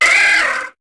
Cri d'Osselait dans Pokémon HOME.